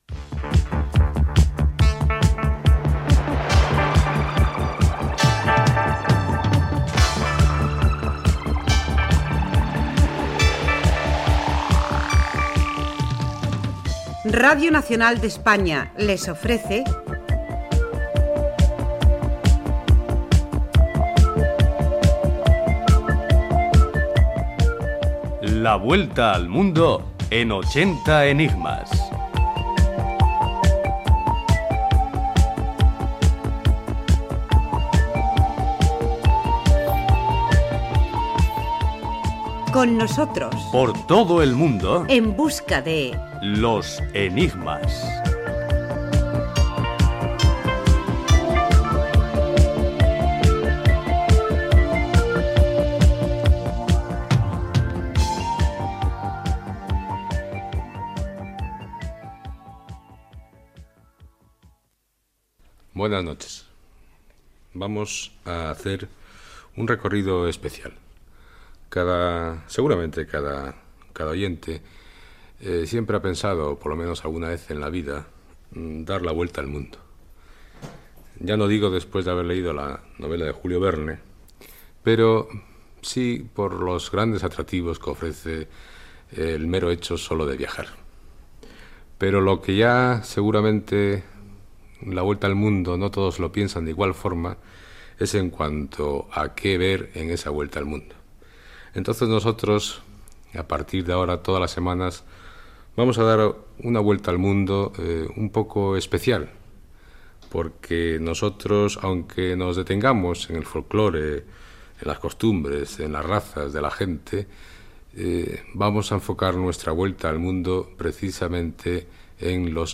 Careta, presentació del que s'oferirà cada setmana, indicatiu
Gènere radiofònic Divulgació